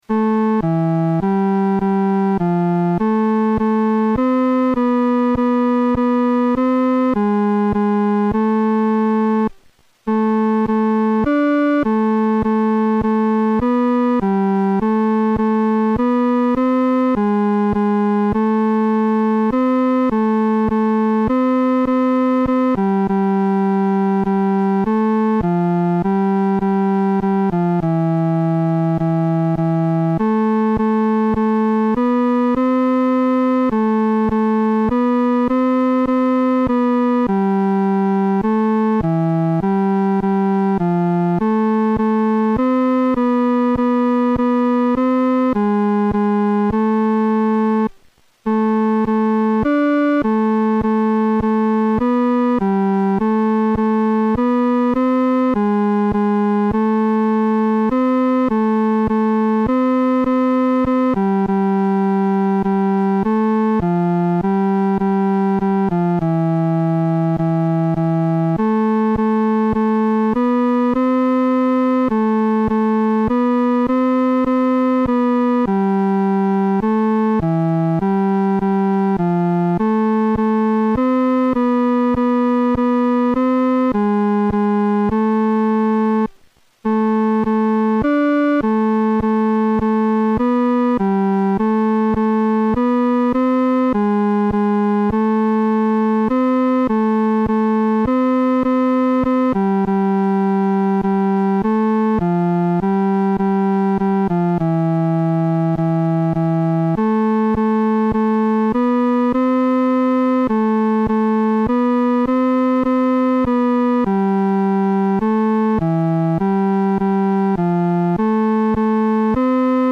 伴奏
男高
曲调开始是商调，非常有力，接着转为羽调，作了一个肯定；然后又转为宫调，有一个稳定的半终止。
这首圣诗的弹唱速度不宜太拖沓。